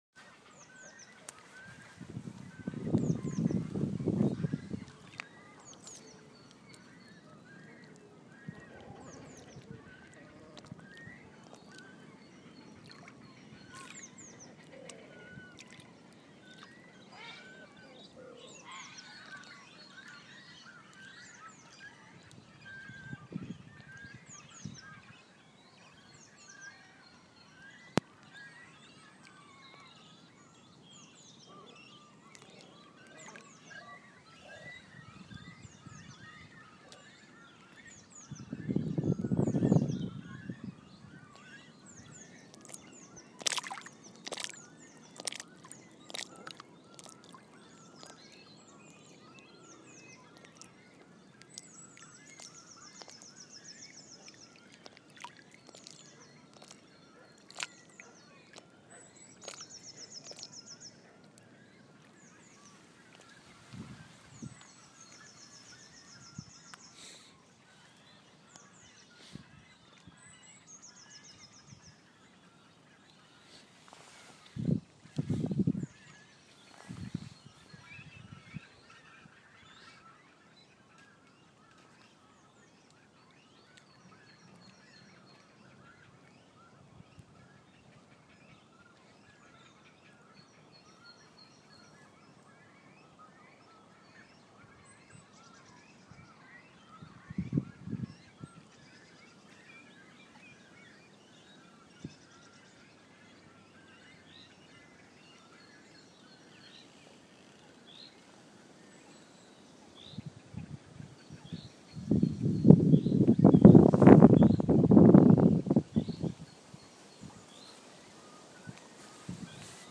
Bird song from the lakes
During a cycle ride out from Walthamstow, in Lea Valley Park